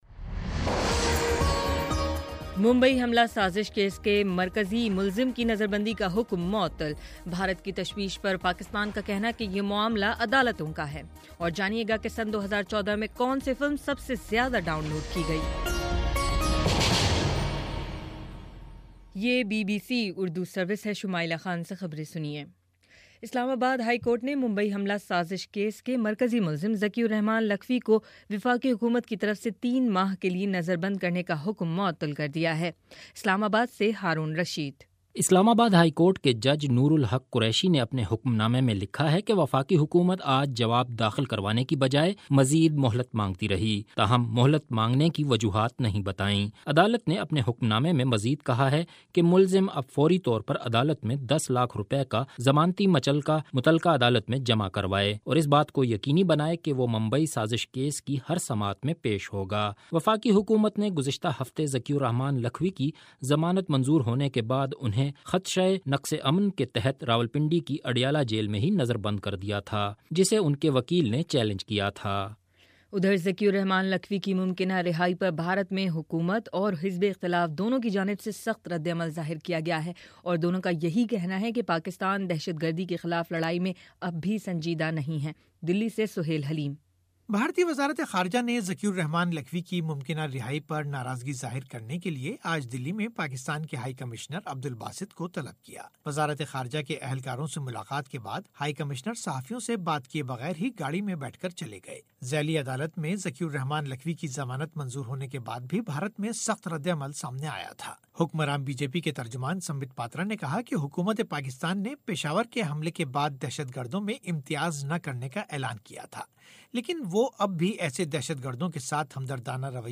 دسمبر29: شام سات بجے کا نیوز بُلیٹن